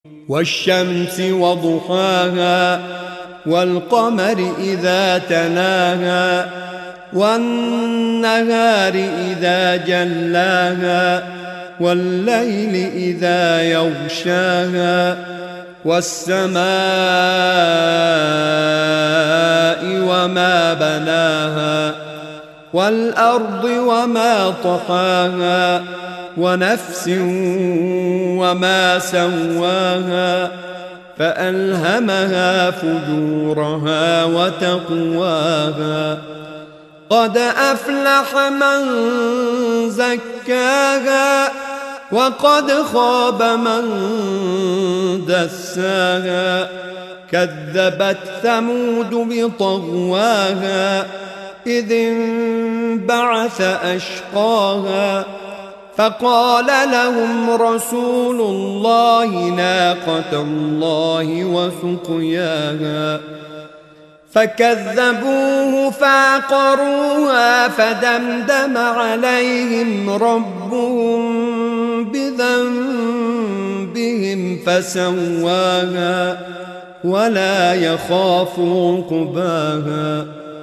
سوره شمس آیات 1 تا 15/ نام دستگاه موسیقی: صبا